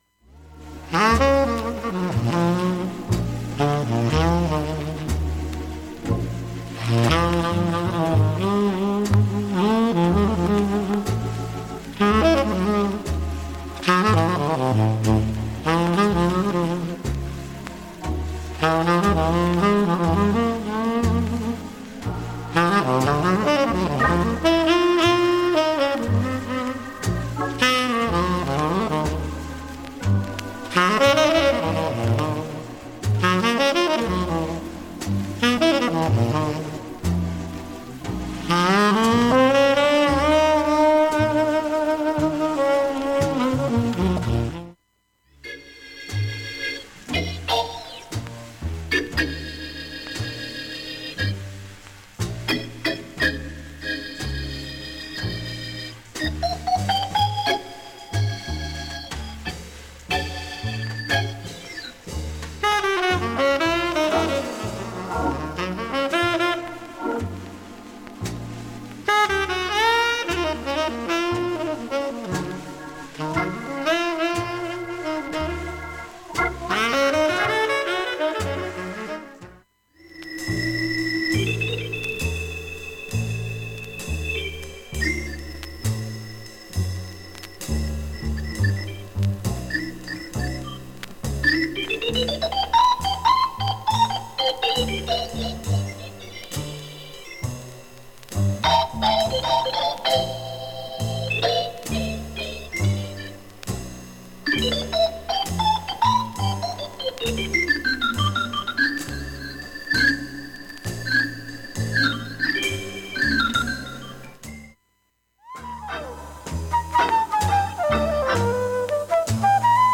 音質良好全曲試聴済み。
70秒間わずかな周回プツが出ています。